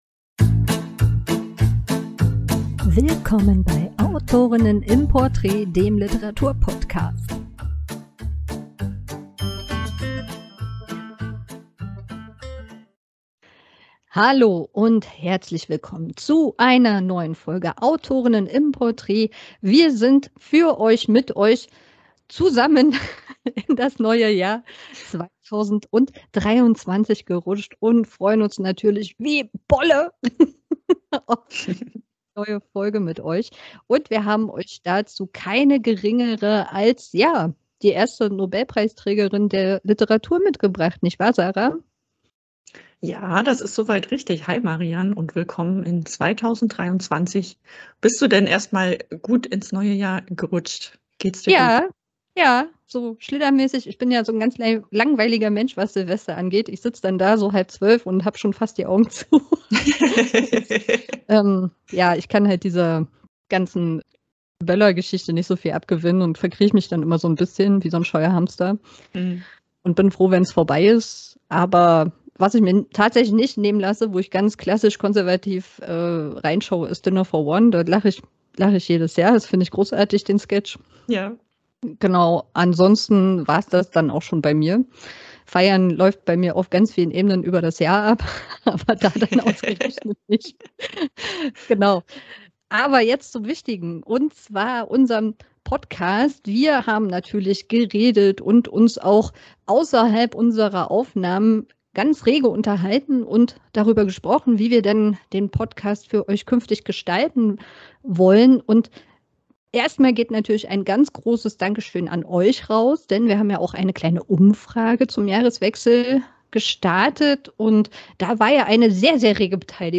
Sie ist nicht nur die Autorin des weltbekannten Romans Die wunderbare Reise des kleinen Nils Holgersson mit den Wildgänsen, sondern kümmerte sich auch um einen Jungen, der tatsächlich diesen Namen trug. Lauscht unserem Gespräch über Selma Lagerlöf!